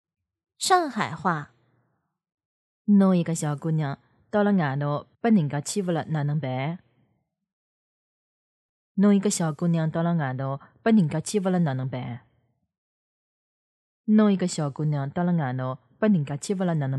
电影对白-青年女声